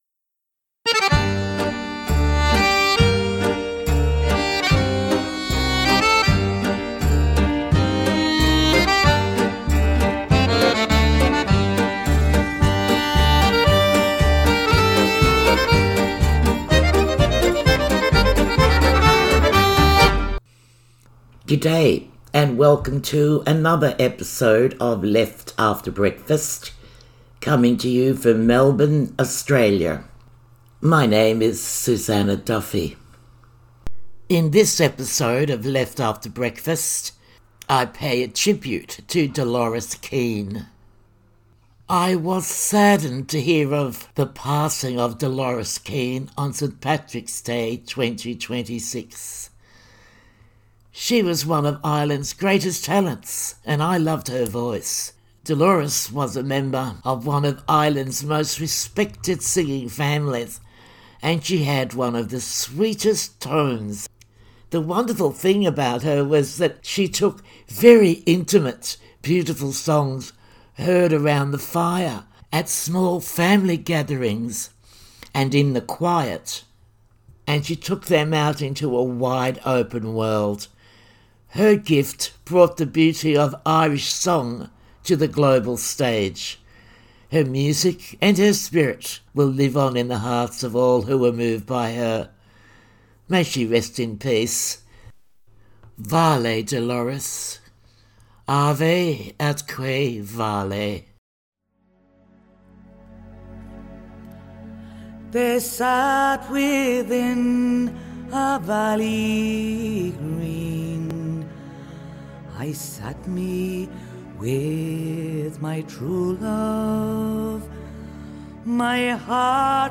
Songs of a singular voice. Her gift brought the beauty of Irish song to the global stage,